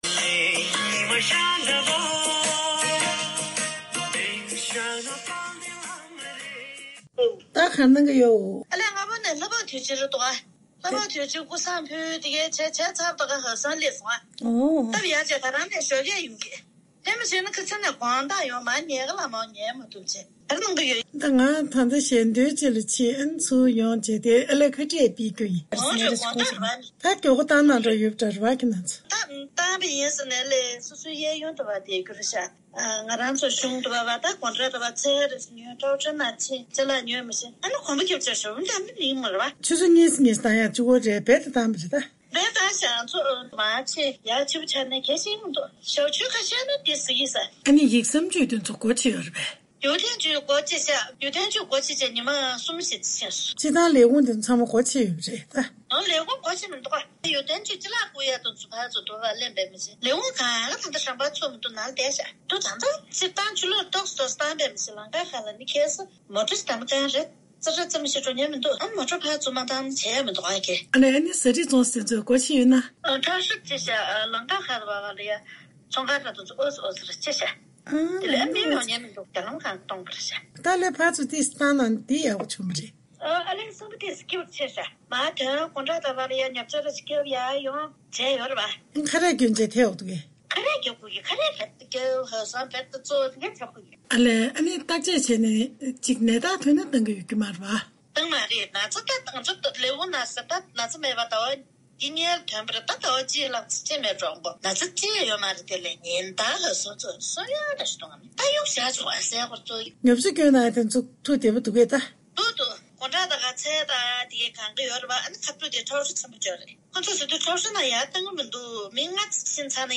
དམིགས་བསལ་བོད་ནང་ཐད་ཀར་ཞལ་དཔར་བརྒྱུད་བཅའ་འདྲི་ཞུས་པ་དེ་གསན་རོགས་གནང་།
བོད་ནང་གི་བོད་མི་ཁག་ཅིག་ལ་བཅའ་འདྲི་ཞུས་པ།